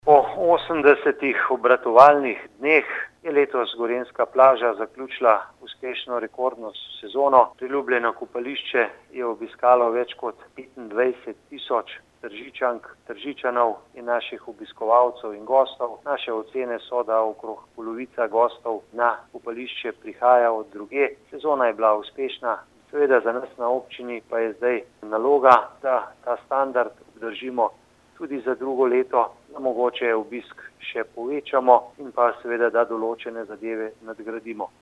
47424_izjavazupanaobcinetrzicmag.borutasajovica_gorenjskaplazazakljucilaseozno.mp3